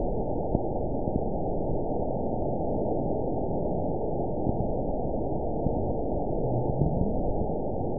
event 921925 date 12/22/24 time 21:22:16 GMT (11 months, 2 weeks ago) score 8.11 location TSS-AB04 detected by nrw target species NRW annotations +NRW Spectrogram: Frequency (kHz) vs. Time (s) audio not available .wav